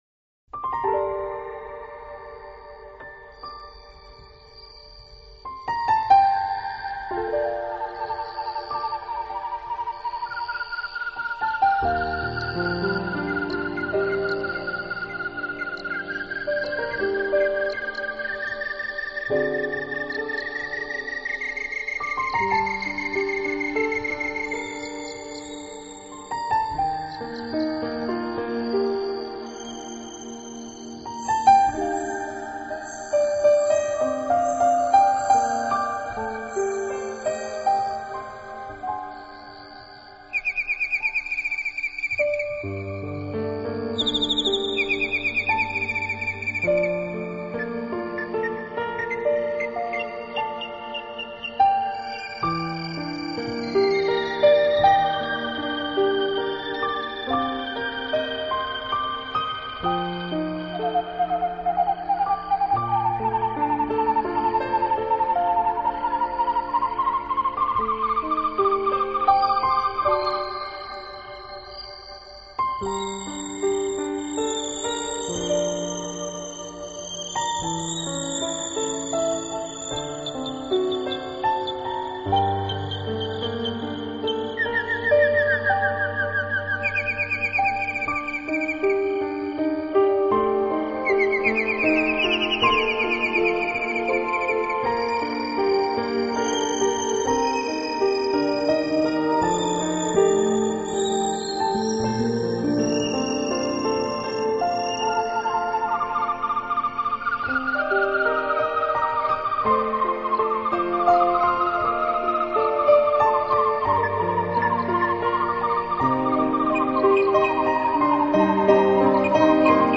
[New Age]适合这个季节和晚上听的一首曲子,很宁静.强烈推荐！